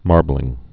(märblĭng)